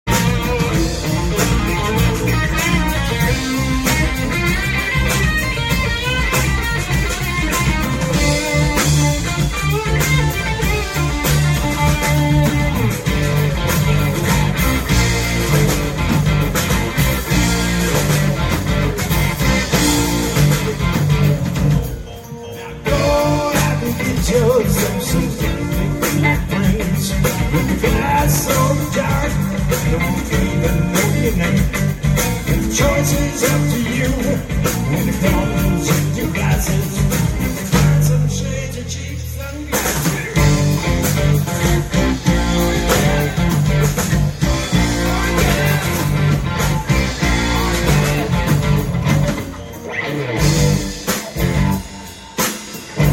Live at the platte River Grill MHK Sound